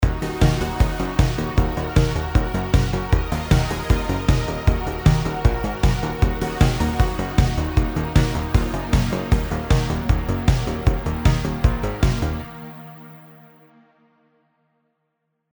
Electronic
Driving